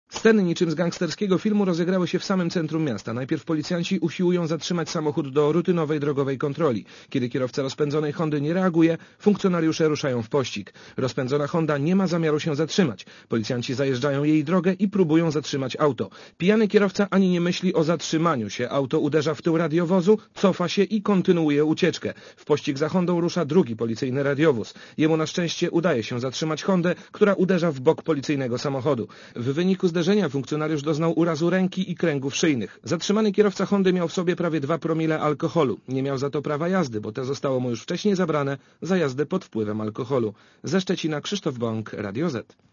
Relacja reportera Radia Zet (166Kb)